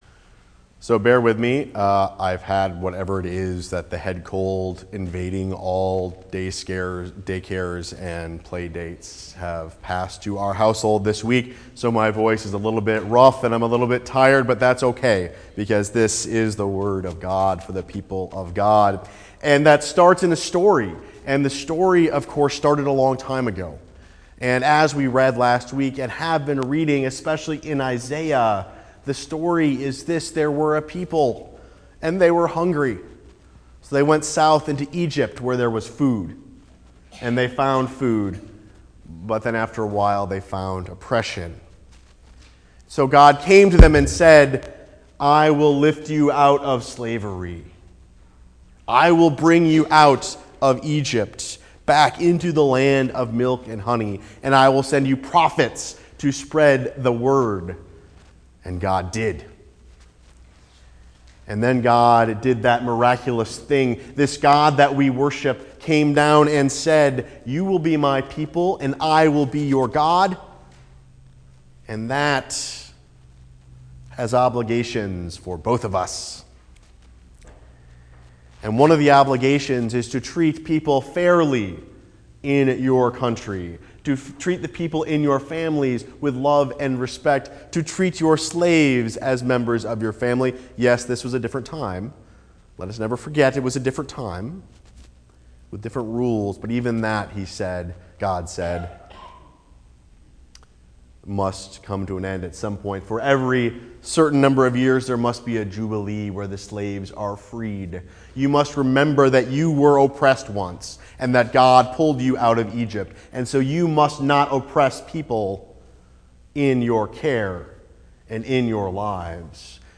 Preached on February 9, 2014 Sermon Text Matthew 5:13-20